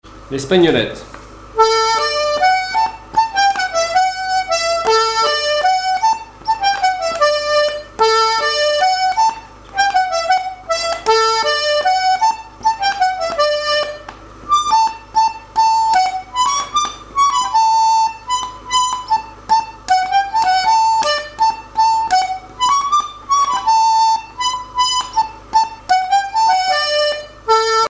danse
airs de danse à l'accordéon diatonique
Pièce musicale inédite